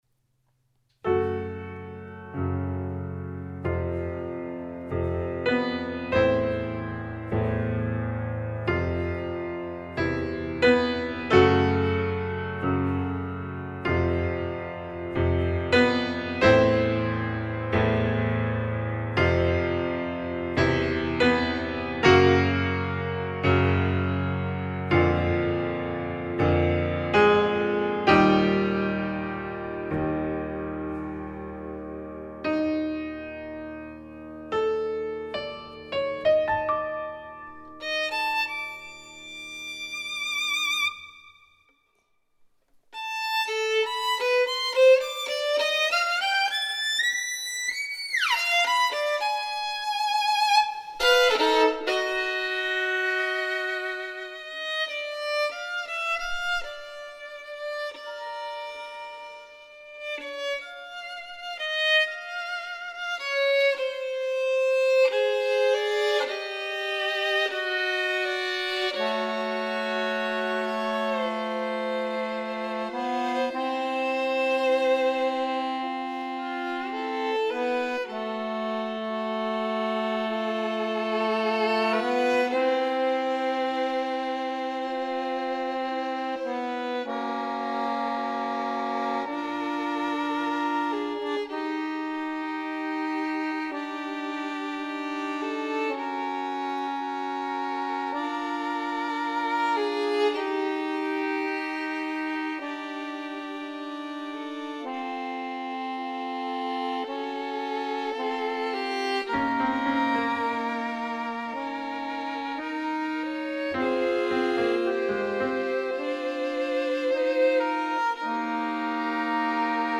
Fisarmonica, Violino e Pianoforte